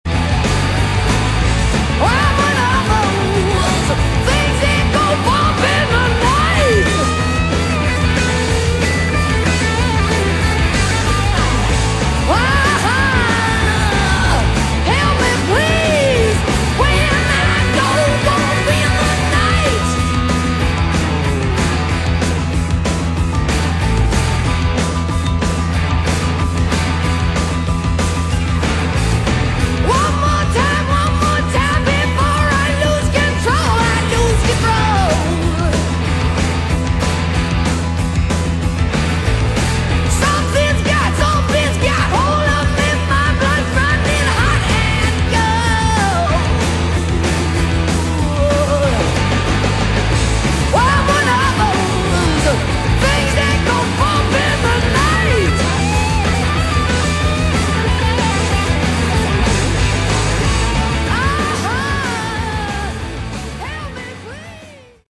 Category: Hard Rock
vocals
guitar
drums
keyboards, bass